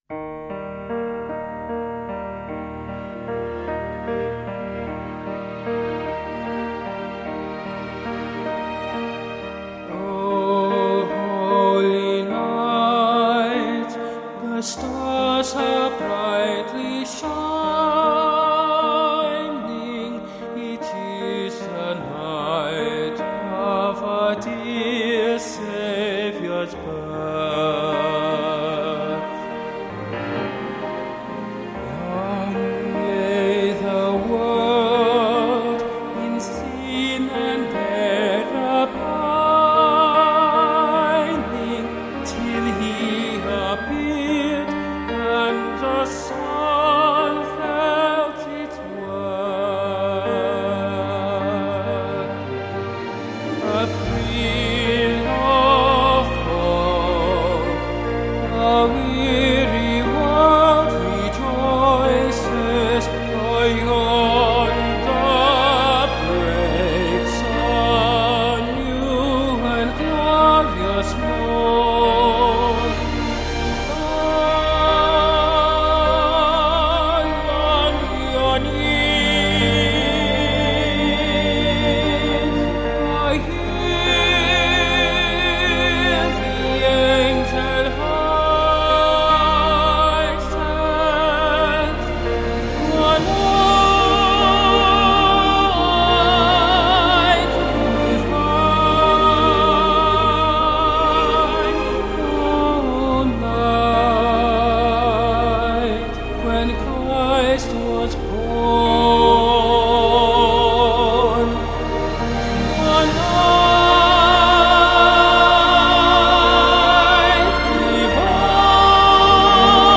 His is a gentle voice. A kind one.